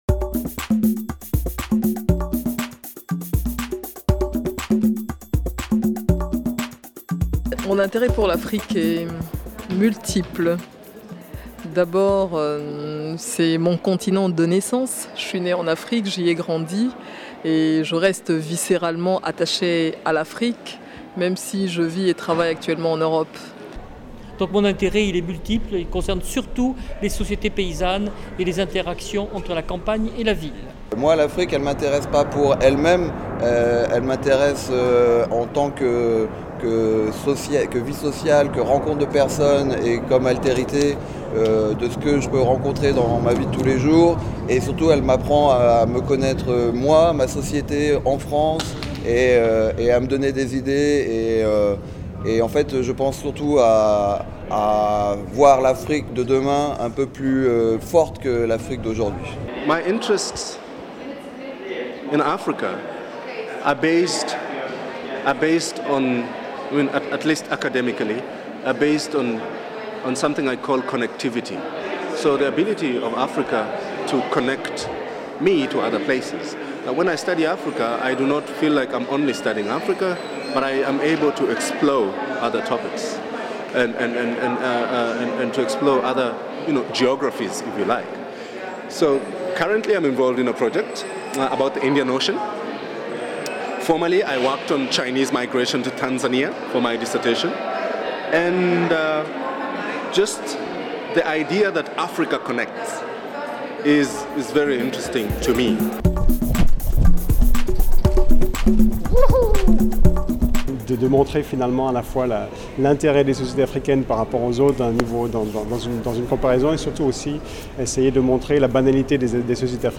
Micro-trottoir réalisé auprès des participants